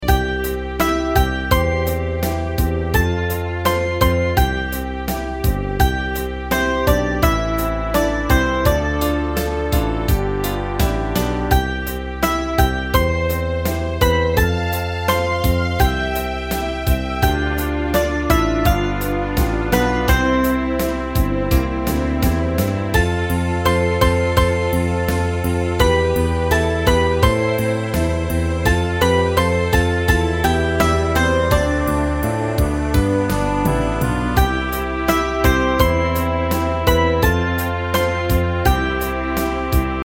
Unison musical score and practice for data.